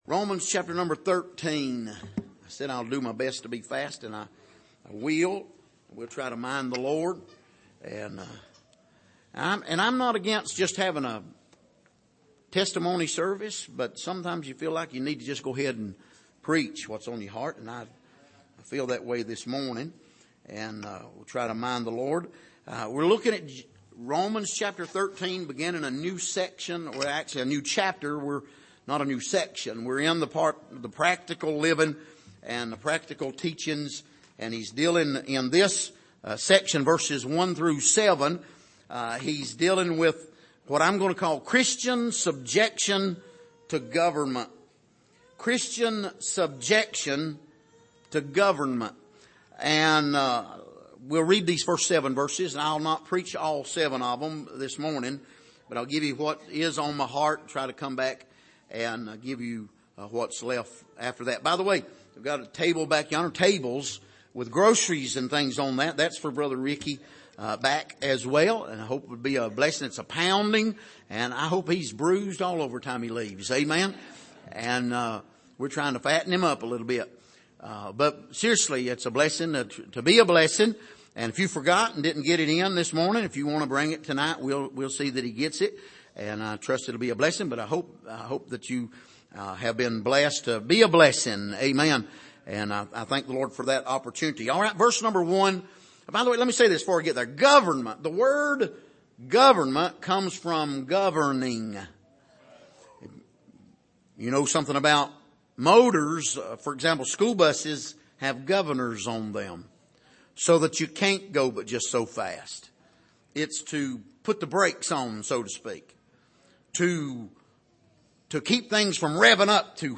Passage: Romans 13:1-7 Service: Sunday Morning